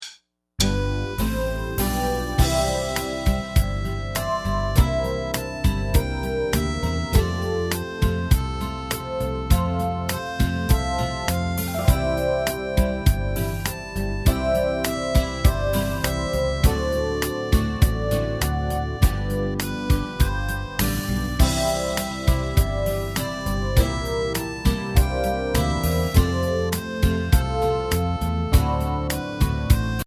Voicing: Harmonica